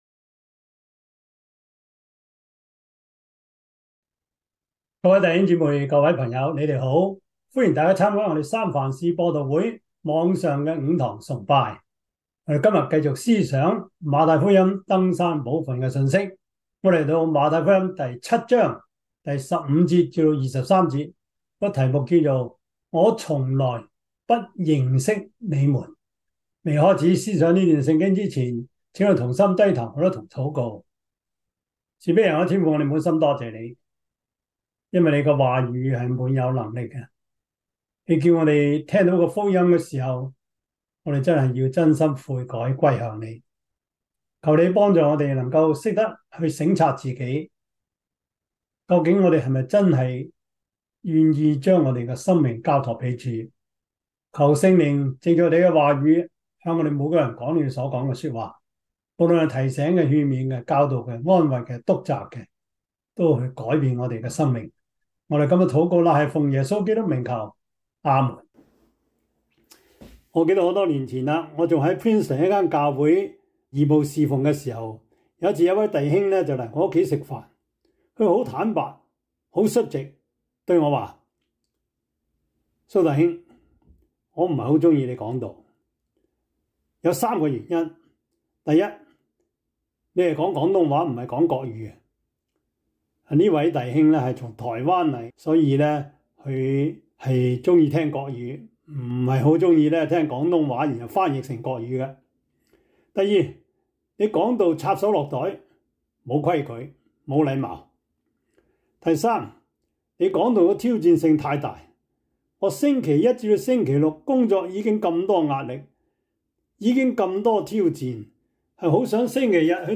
2023 主日崇拜 Passage